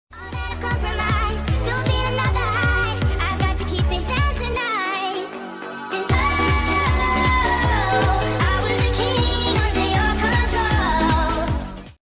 *Sound Driver* Plana bus salou sound effects free download
4 to Cambrils | @ Passeig de Jaume 1